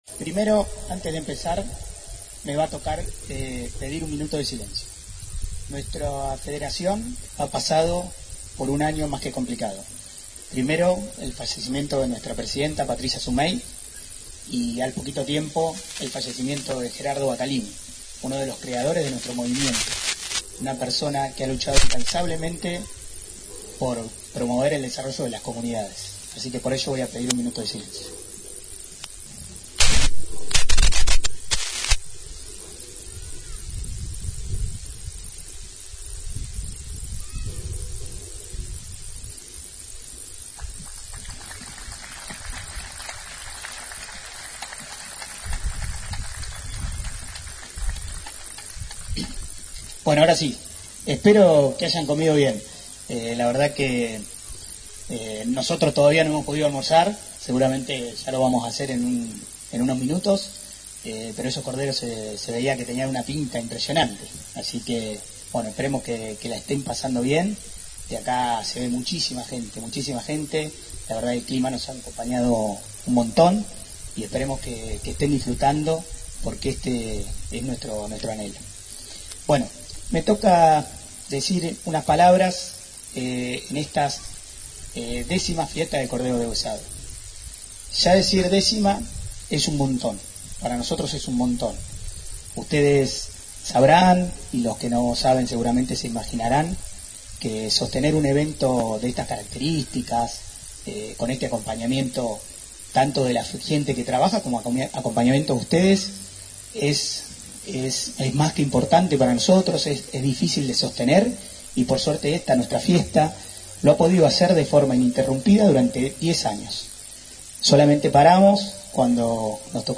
Luego se sirvió el almuerzo y llegaron los discursos y reconocimientos por la labor en estos 10 años del gran evento